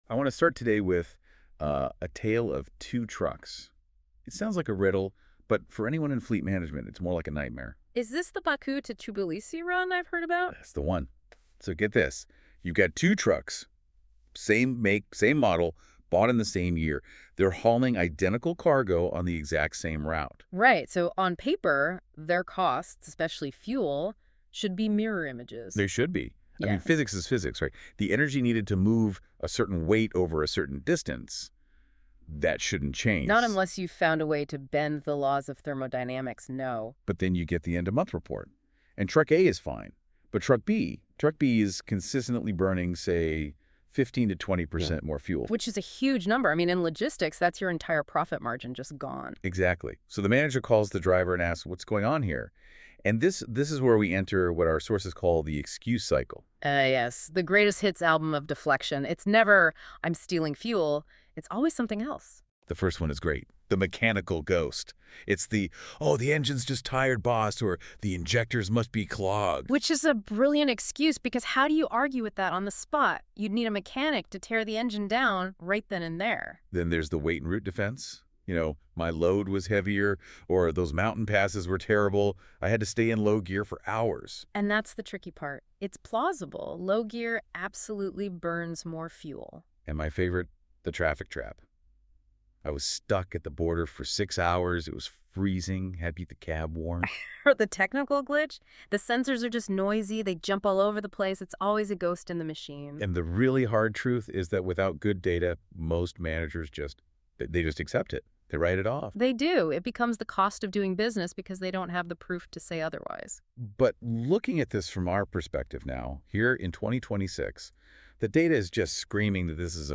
Press play below to hear the AI-generated podcast version of this article.